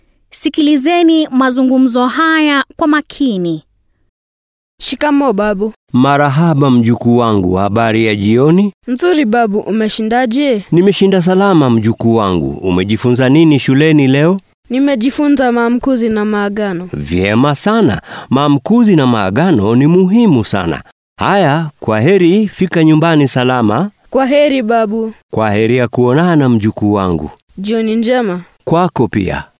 Rekodi 1 Suala kuu la 1 - Mazungumzo.mp3